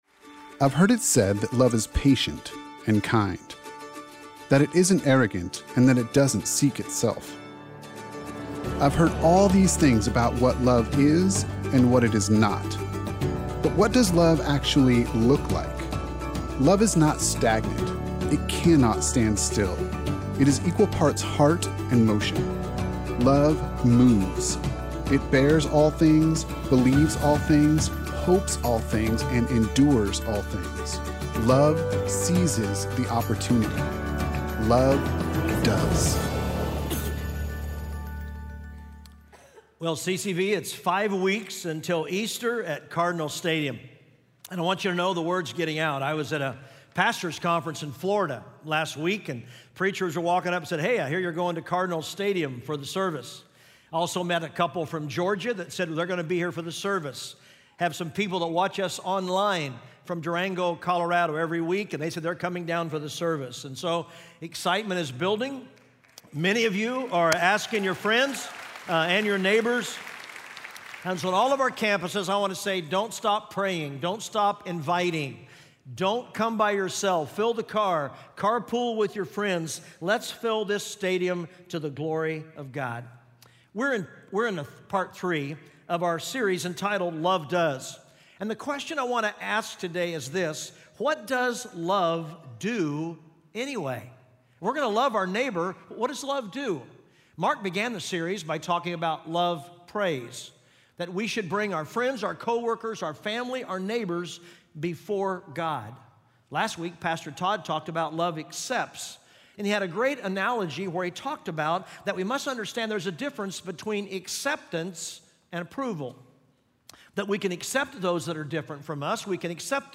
Love Does: Invest (Full Service)